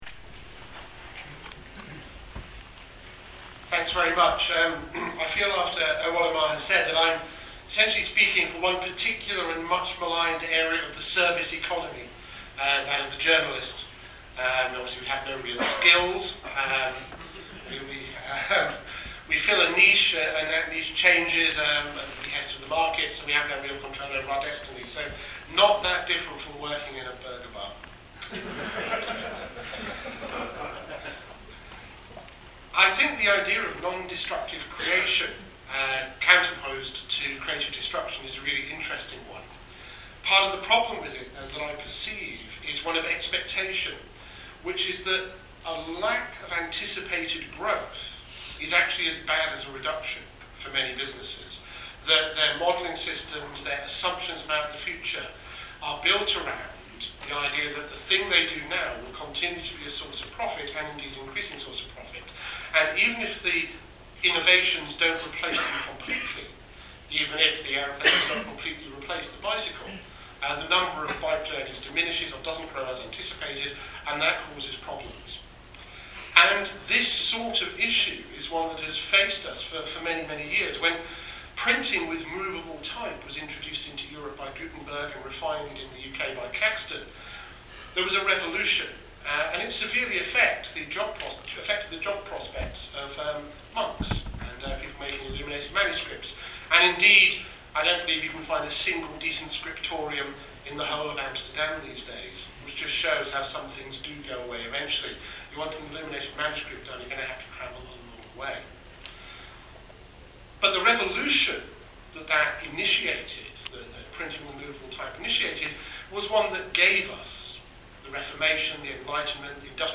na svojem amsterdamskem predavanju